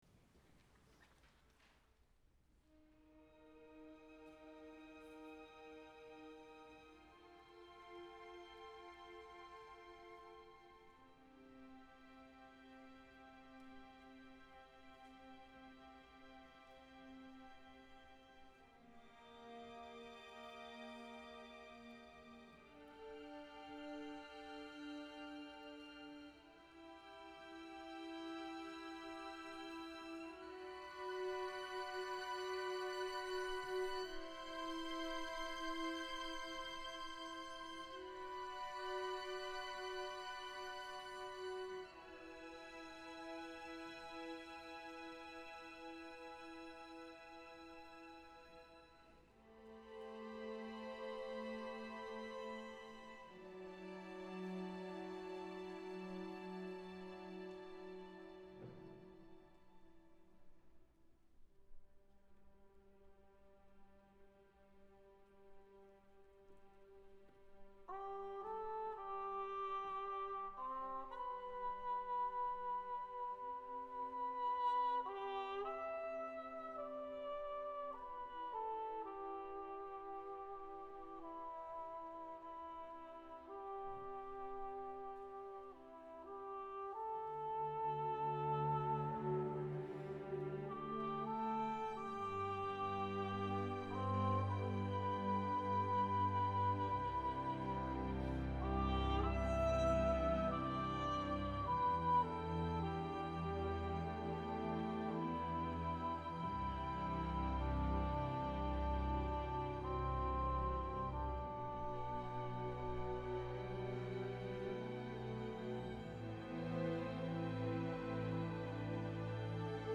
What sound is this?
tpt Genre: Classical.